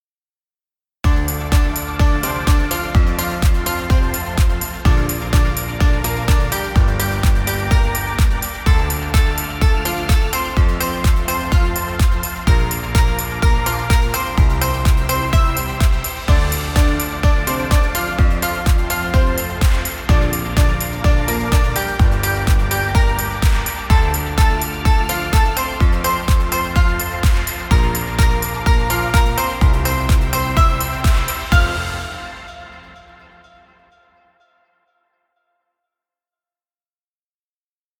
Uplifting corporate music.